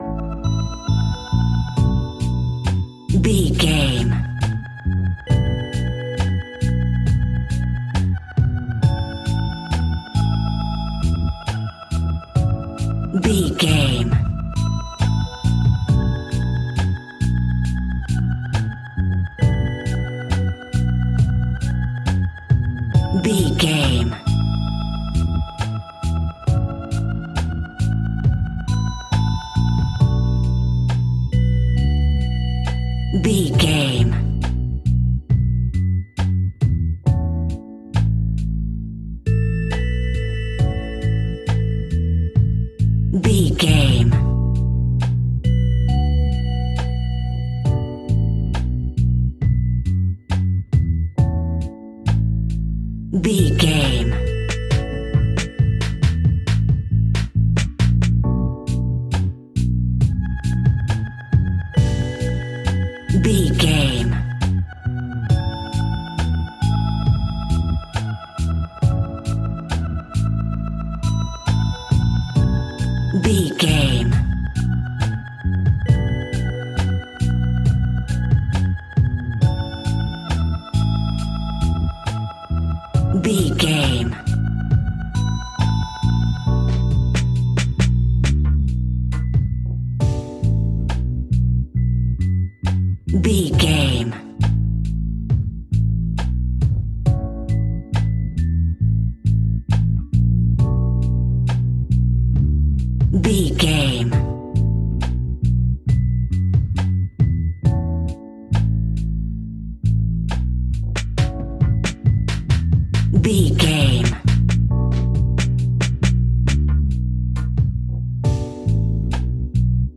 Ionian/Major
A♭
chilled
laid back
Lounge
sparse
new age
chilled electronica
ambient
atmospheric
morphing
instrumentals